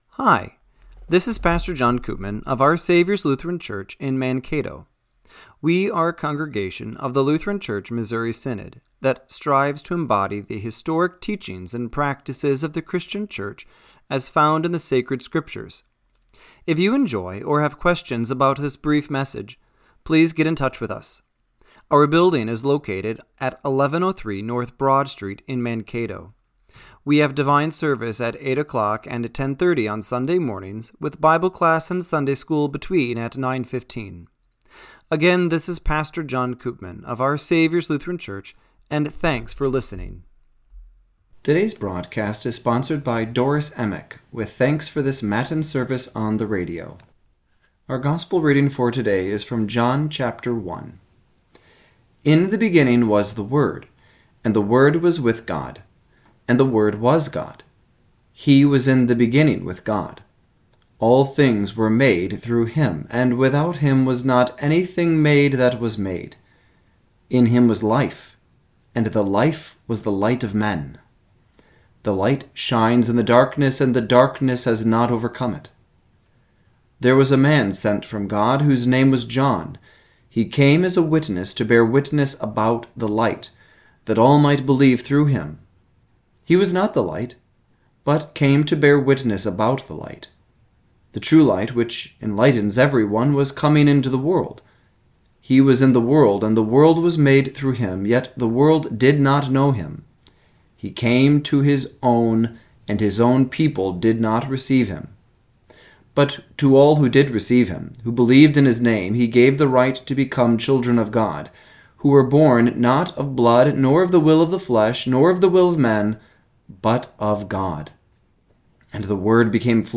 Radio-Matins-12-28-25.mp3